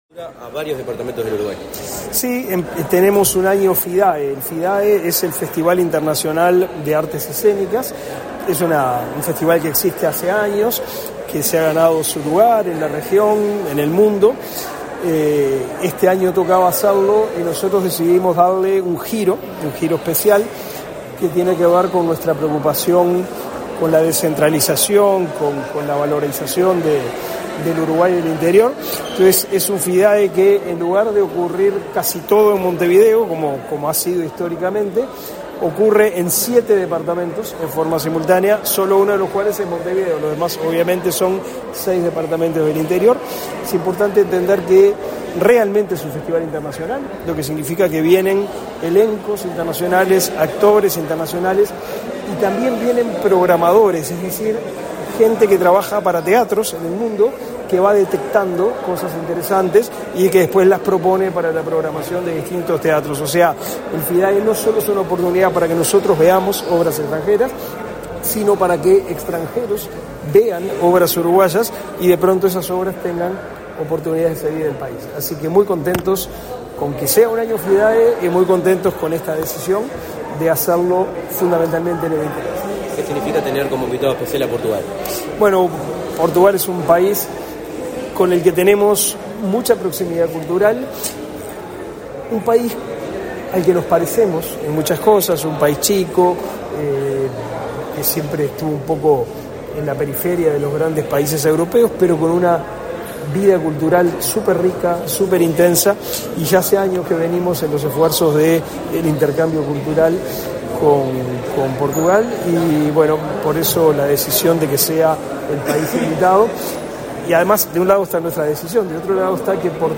Declaraciones a la prensa del ministro del MEC, Pablo da Silveira
Declaraciones a la prensa del ministro del MEC, Pablo da Silveira 12/06/2024 Compartir Facebook X Copiar enlace WhatsApp LinkedIn El Ministerio de Educación y Cultura (MEC) realizó, este 12 de junio, el lanzamiento del Festival Internacional de Artes Escénicas. Tras el evento, el ministro Pablo da Silveira, realizó declaraciones a la prensa.